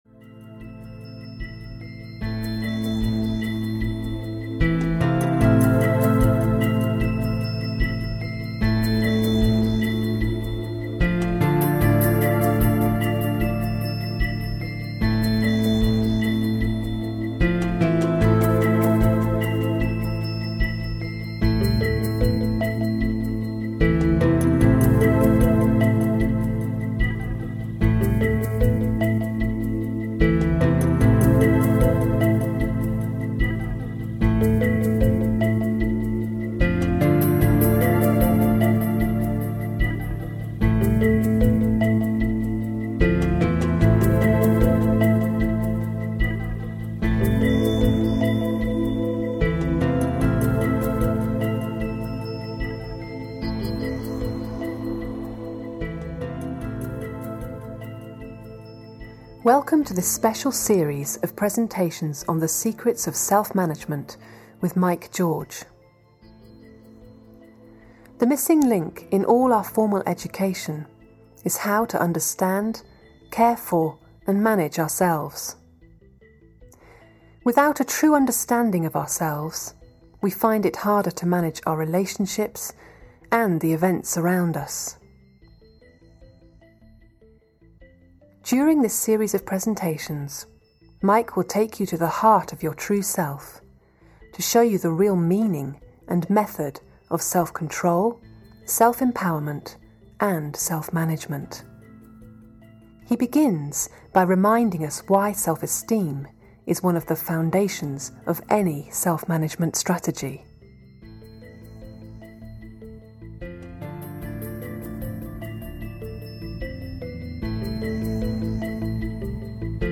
Relaxing meditation instructions accompanied by calm music.
Ukázka z knihy